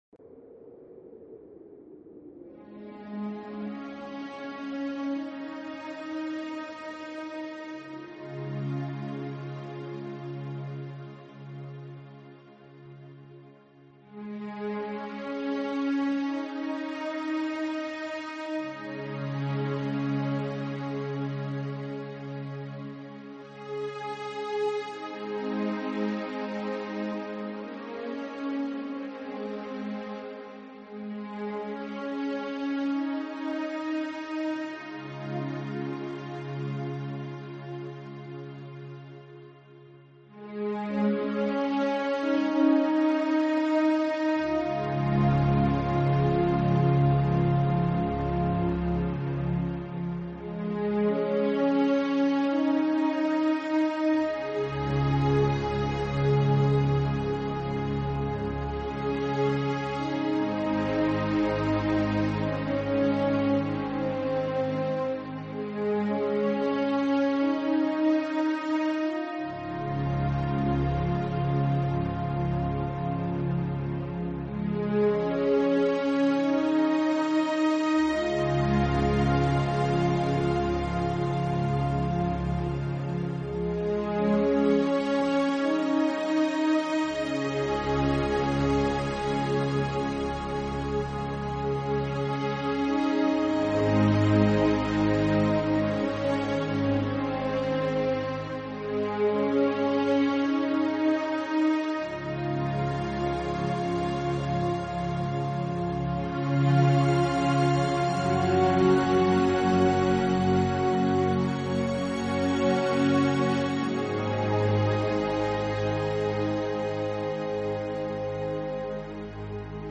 Genre..........: New Age
helps create a relaxing and inspiring atmosphere.